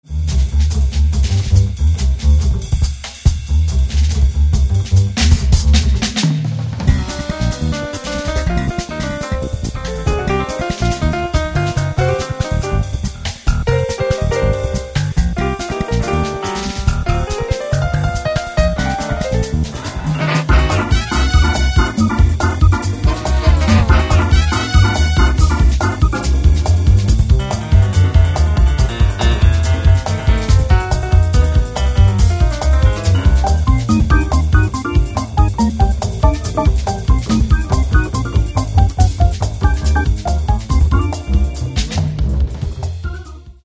Каталог -> Джаз и около -> Сборники, Джемы & Live